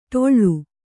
♪ ṭoḷḷu